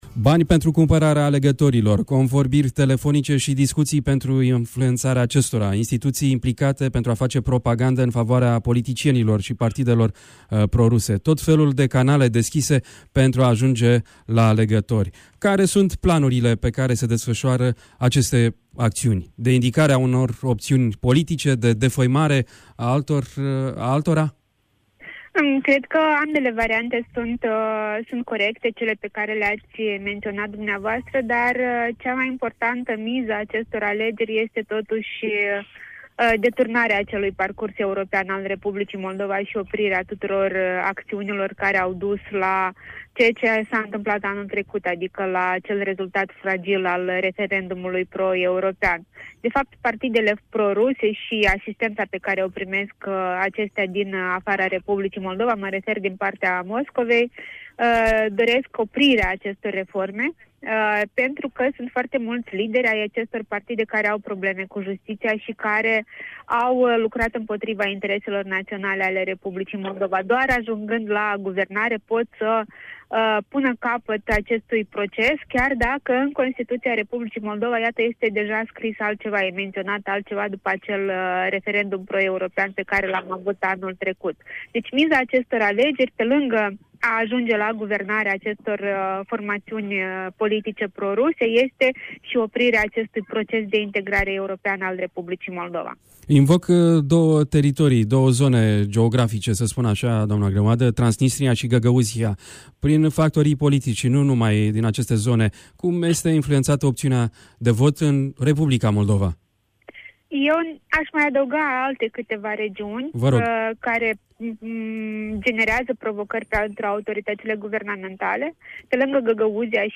Varianta audio a interviului: Share pe Facebook Share pe Whatsapp Share pe X Etichete